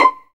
PIZZ VLN C5.wav